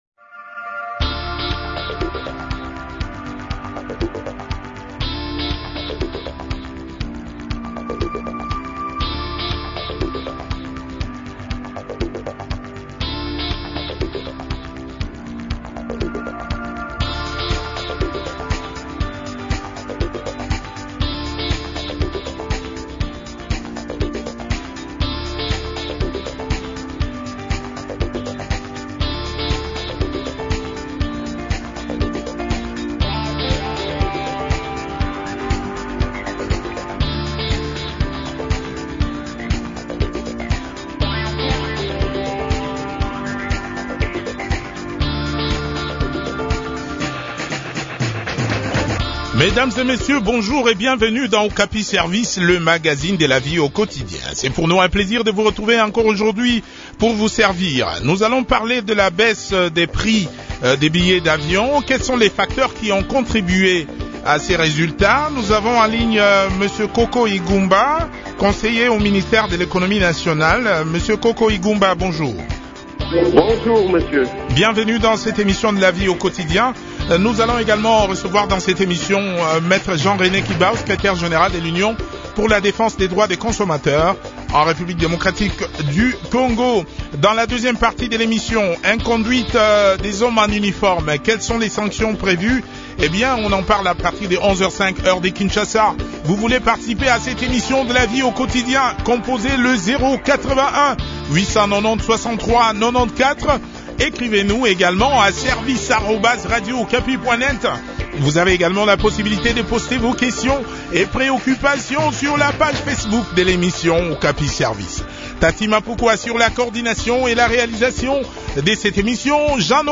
a aussi participé à cette interview.